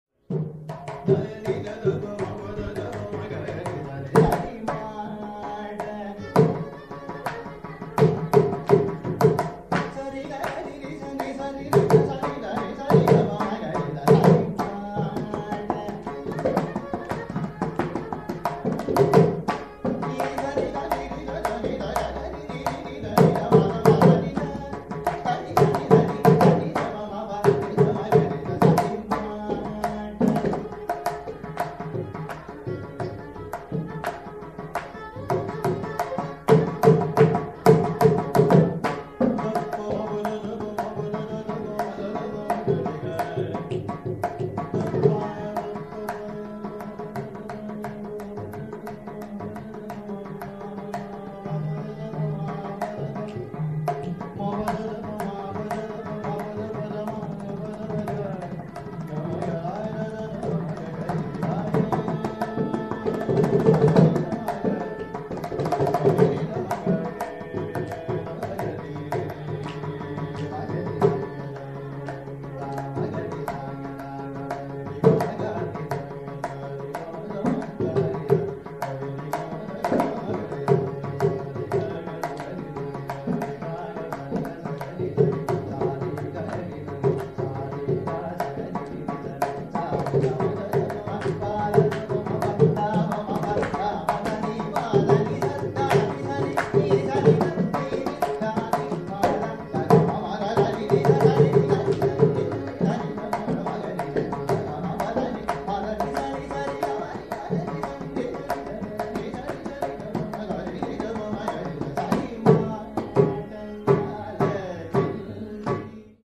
Endowed with a pleasing and flexible voice he could easily scale a 3-octave range.